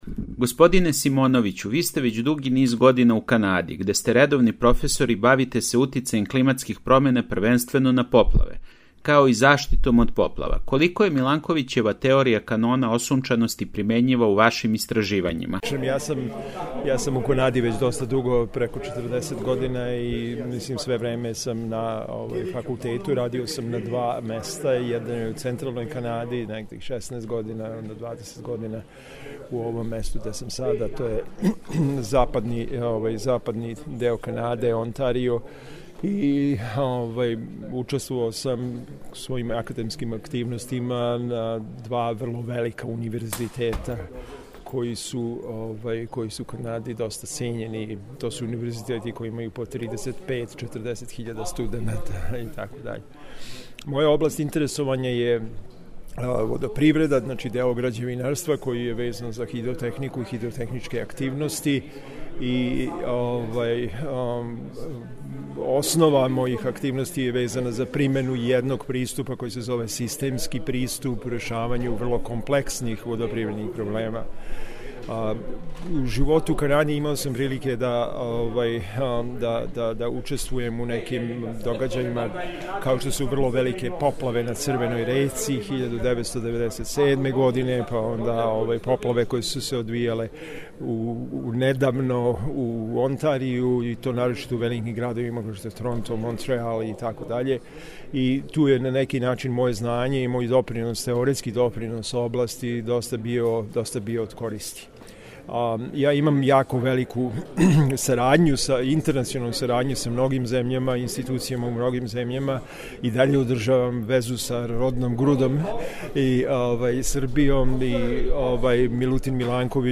Изјава проф.